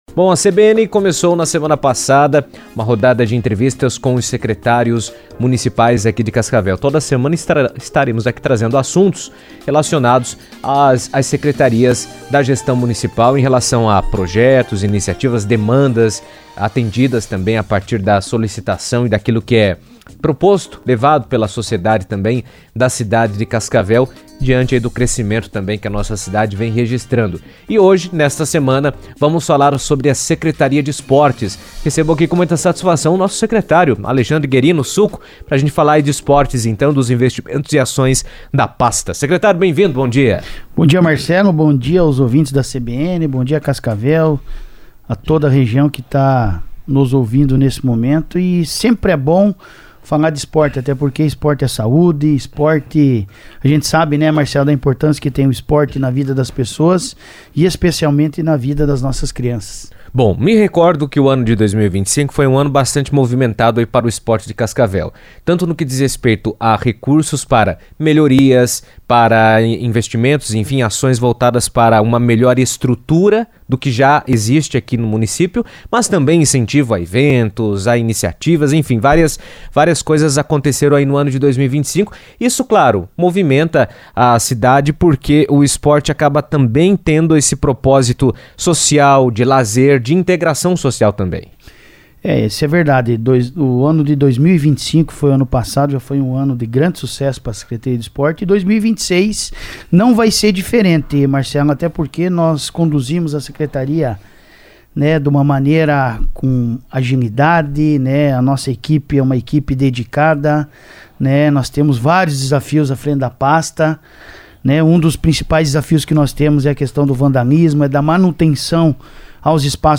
A Secretaria de Esportes de Cascavel anunciou novos investimentos voltados à melhoria de espaços esportivos e à implementação de projetos para a comunidade. Em entrevista à CBN, o secretário municipal de Esporte e Lazer, Alexandre “Suco” Guerino, destacou que as ações têm como objetivo ampliar o acesso às práticas esportivas, incentivar a participação da população e fortalecer iniciativas locais.